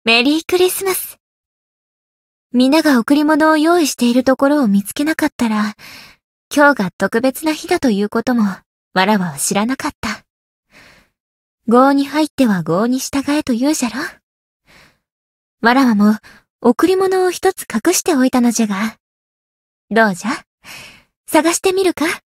灵魂潮汐-蕖灵-圣诞节（相伴语音）.ogg